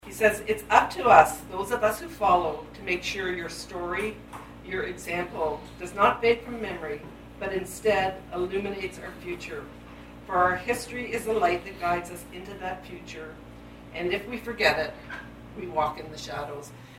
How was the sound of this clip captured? Mayor, M-L-A, and M-P alike were on hand last Saturday at the 100 Mile House Legion to present honours to seven South Cariboo residents for their roles with Canadian armed forces during WWII.